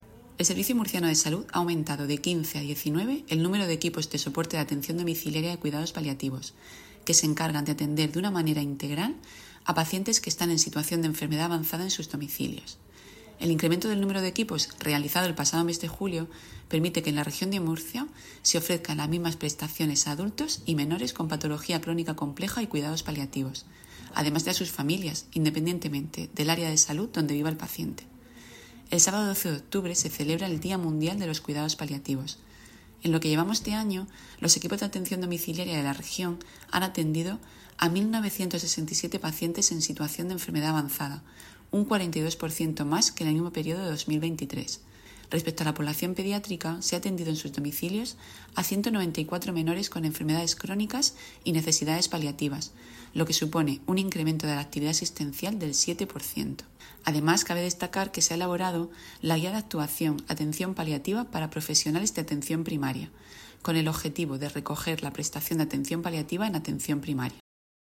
Declaraciones de la gerente del SMS, Isabel Ayala, sobre la atención en cuidados paliativos.